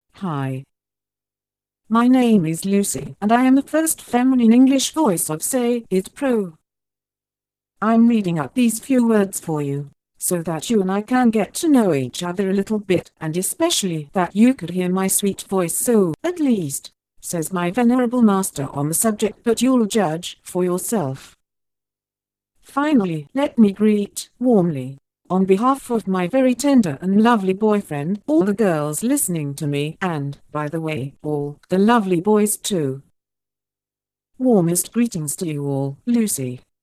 Texte de démonstration lu par Lucy, première voix féminine anglaise de LogiSys SayItPro (Version 1.70)